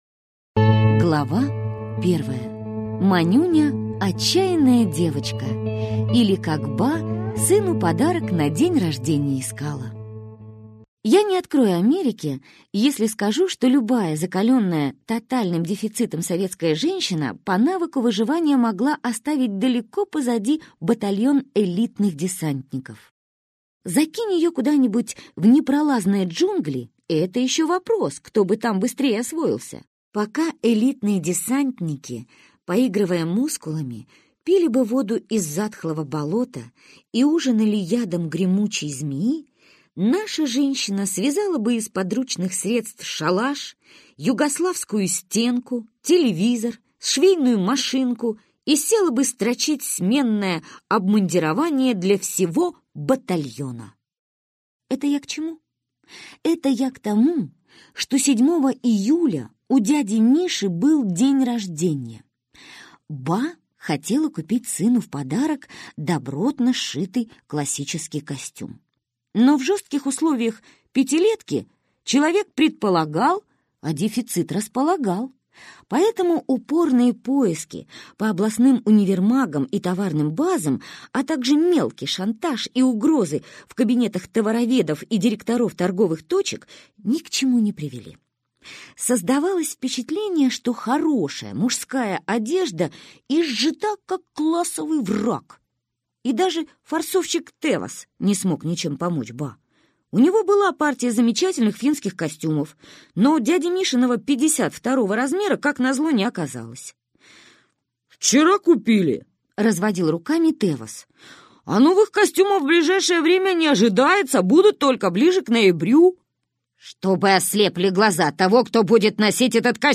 Аудиокнига Манюня пишет фантастичЫскЫй роман - купить, скачать и слушать онлайн | КнигоПоиск